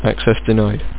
Amiga 8-bit Sampled Voice
Denied.mp3